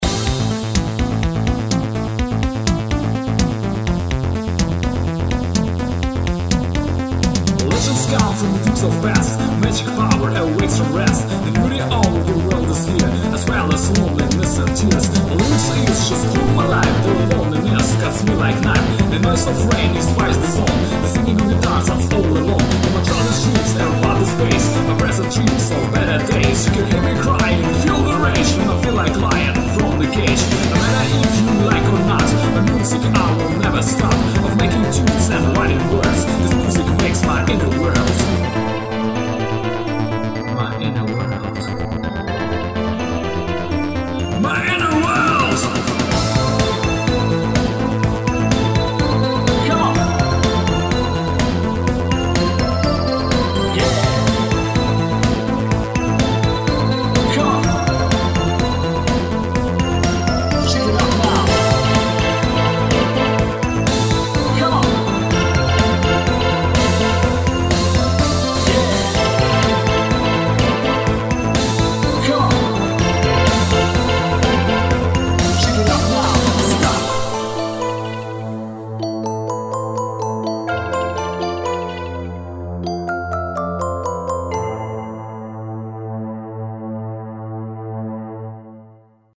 Песенные композиции: